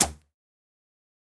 Arrow Release 1.wav